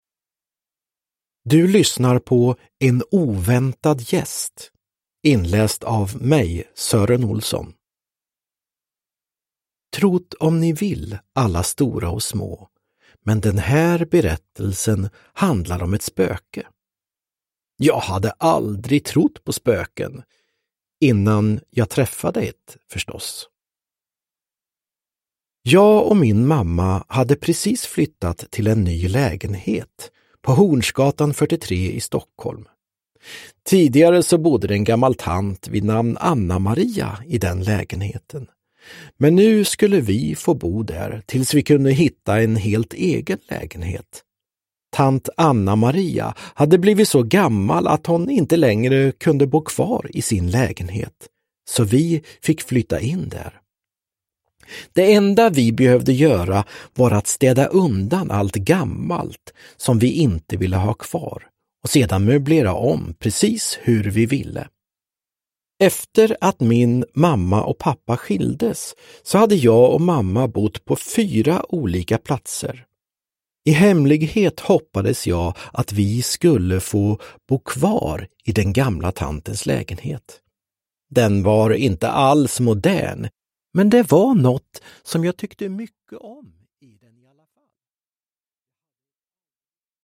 En oväntad gäst – Ljudbok – Laddas ner
Uppläsare: Sören Olsson, Anders Jacobsson